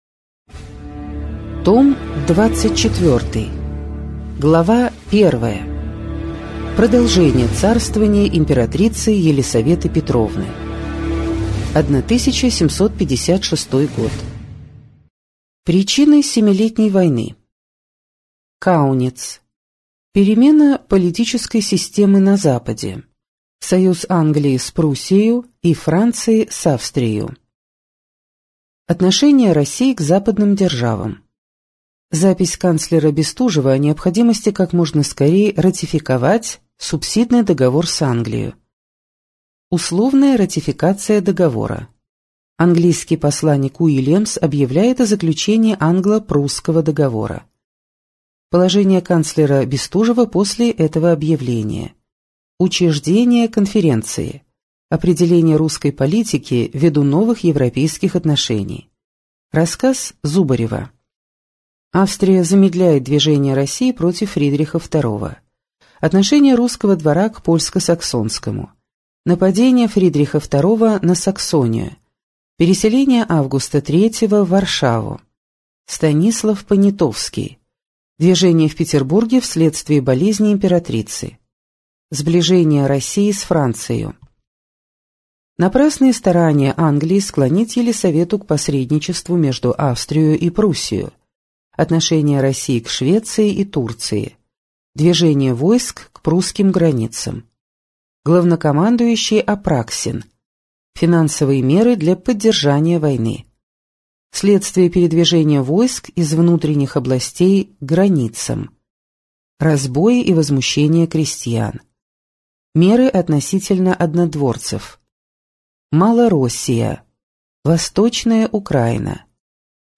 Аудиокнига История России с древнейших времен. Том 24 | Библиотека аудиокниг